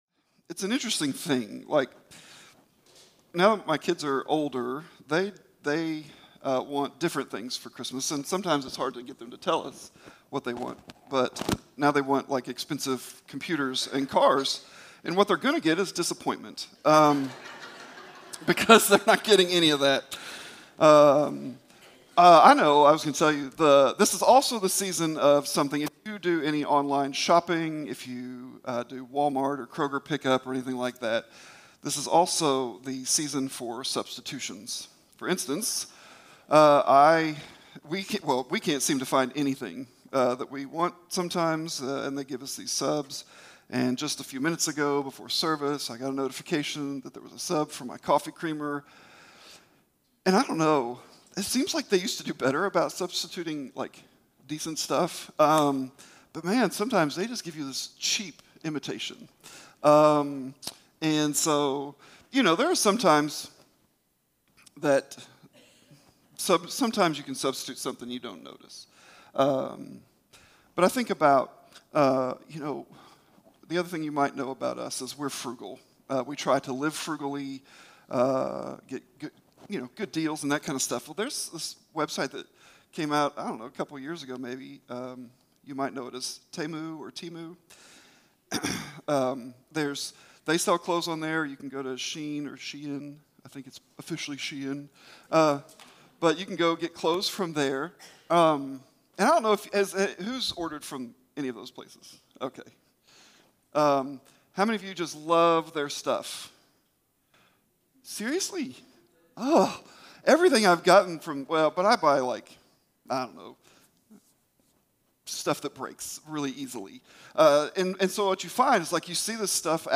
Sermons | Campbellsville Christian Church